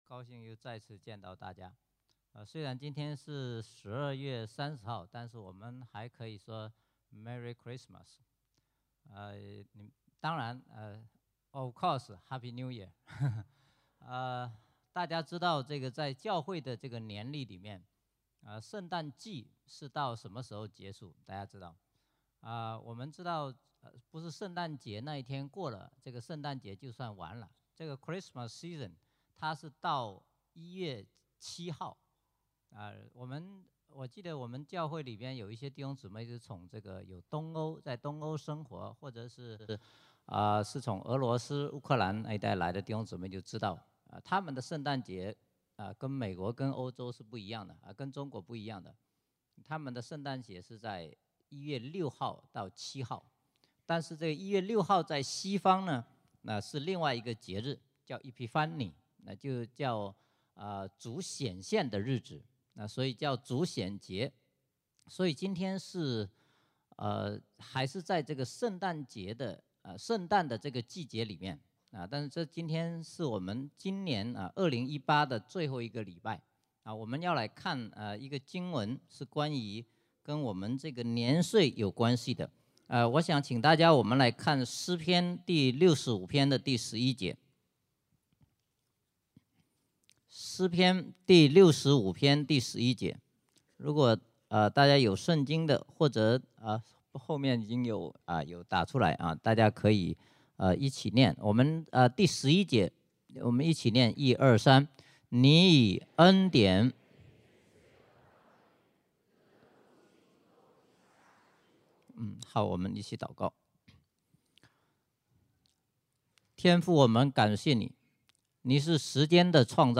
IMS Sermons